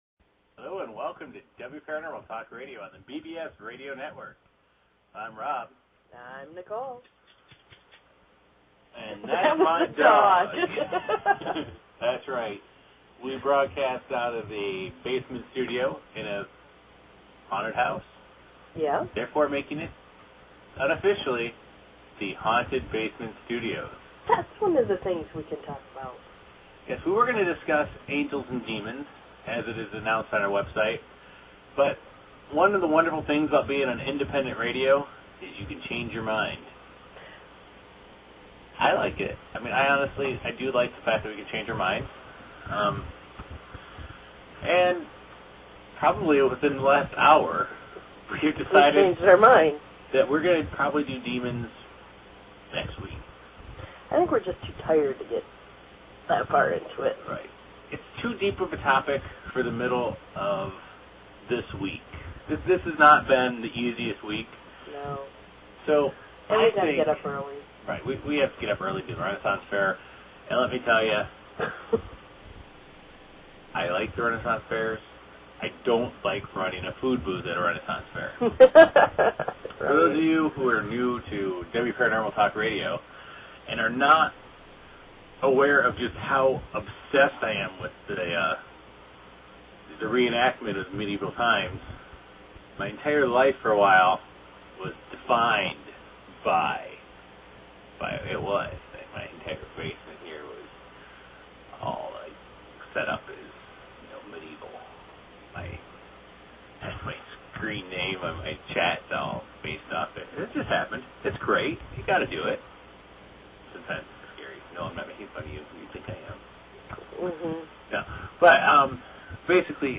Talk Show Episode, Audio Podcast, Wparanormal and Courtesy of BBS Radio on , show guests , about , categorized as